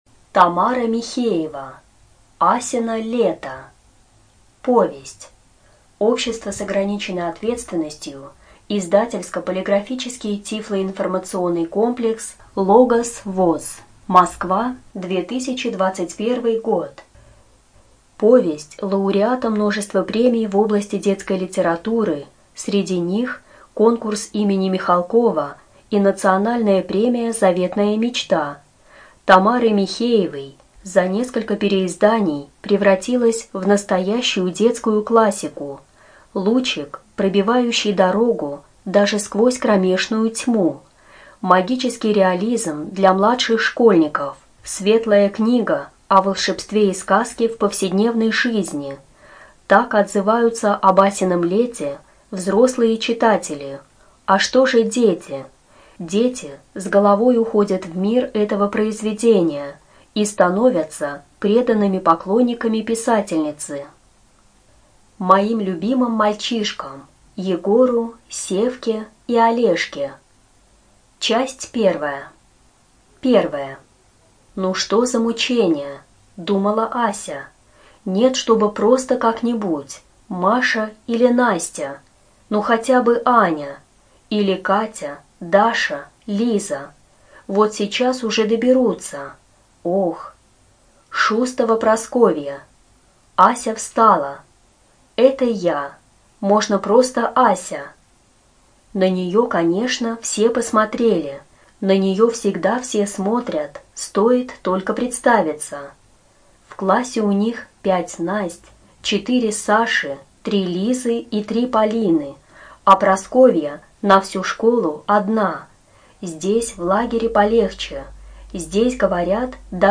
ЖанрДетская литература, Сказки
Студия звукозаписиБелгородская областная библиотека для слепых имени Василия Яковлевича Ерошенко